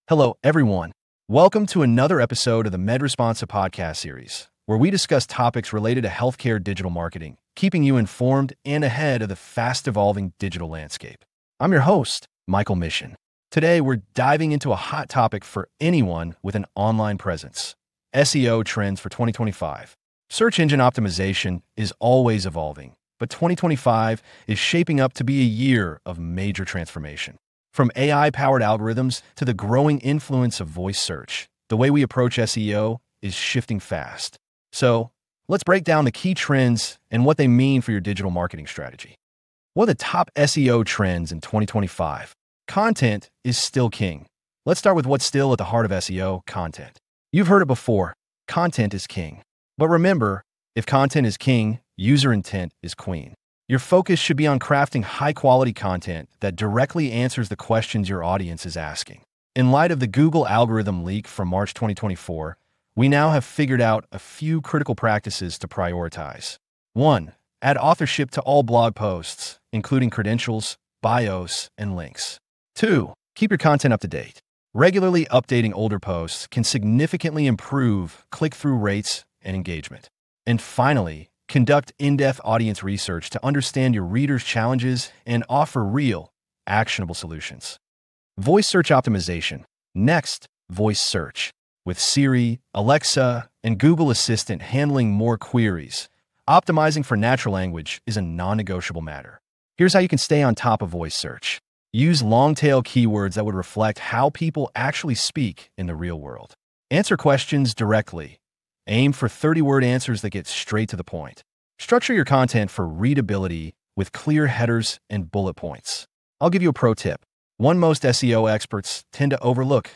Disclaimer: This podcast contains human-authored content presented through an AI-generated voice.